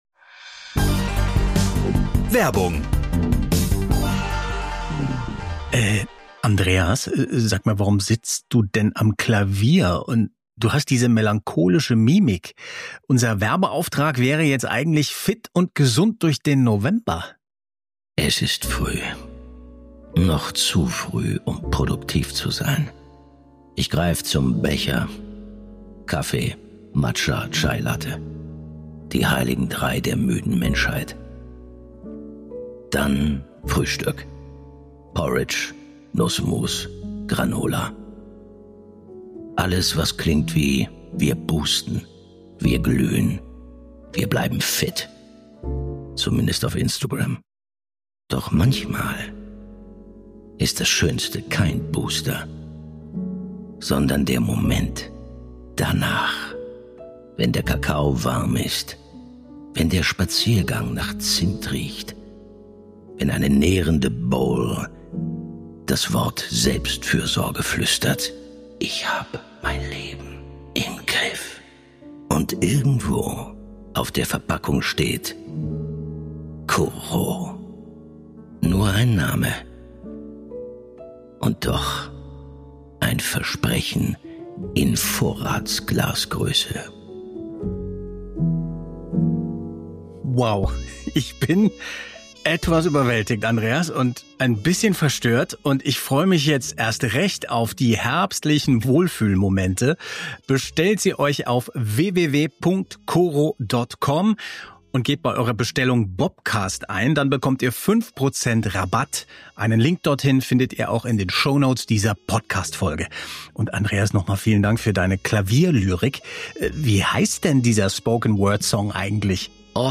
Umfassend und in chronologischer Reihenfolge konzentrieren sich die Bobcast-Macher auf die Anfänge der Serie; immer mit O-Tönen und Musik aus den Hörspielen sowie einem Blick ins Original-Manuskript.